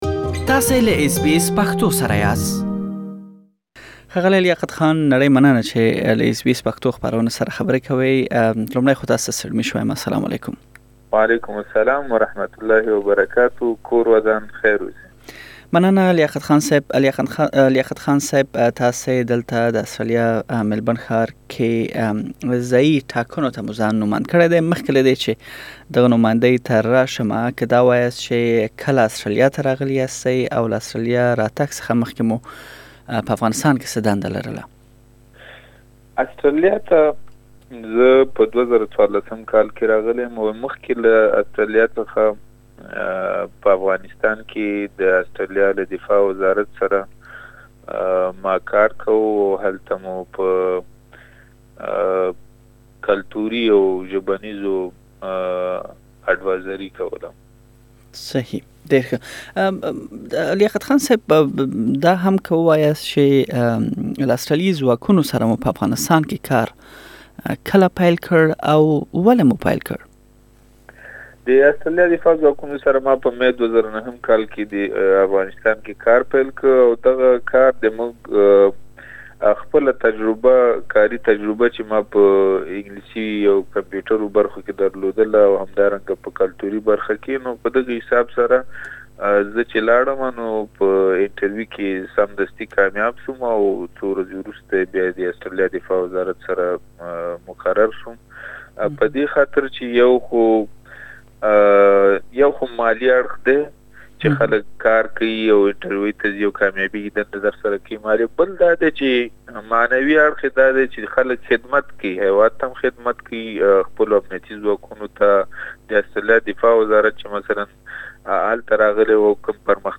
تاسې د ښاغلي بشپړه مرکه دلته اوريدلی شئ.